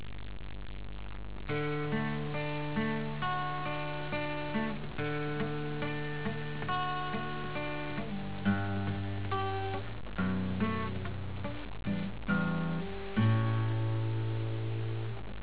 音がミュートしてしまい、まともに出ない。しかもミストーンの連発。